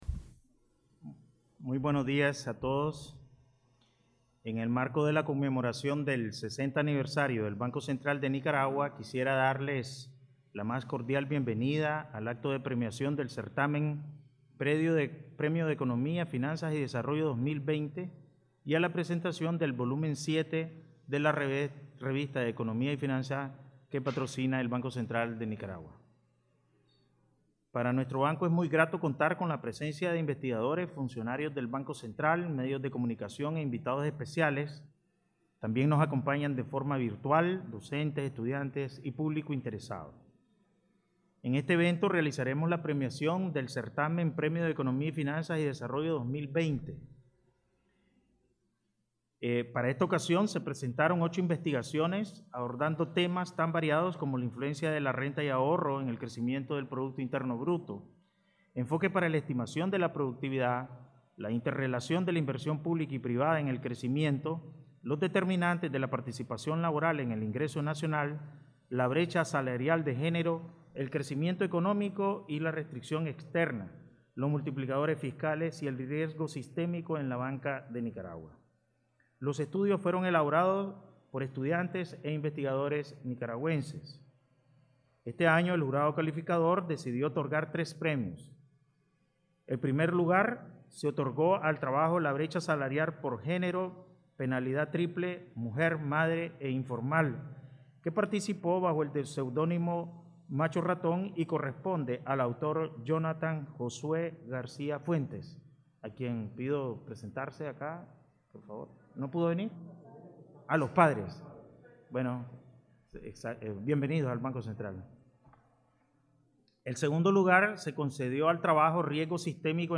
BCN realiza ceremonia de premiación del Premio de Economía, Finanzas y Desarrollo 2020
Palabras del Presidente del BCN, Cro. Ovidio Reyes R.